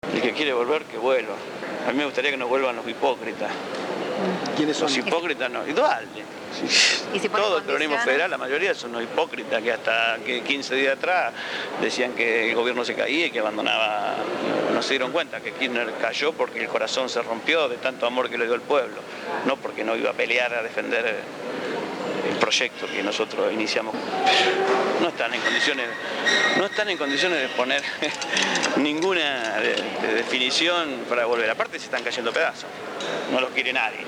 Entrevistado